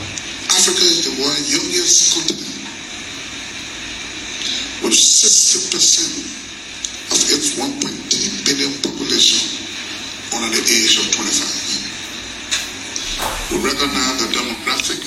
V.P. Koung was making a case at the Young Political Leadership School Africa Cohort 11, about the continent’s “demographic advantage and the caliber of young people that will shape the future development of the African continent and its people”.
V.P Jeremiah Koung speaking at YPLSA Cohort 11- Photo Credit: Office of the Vice President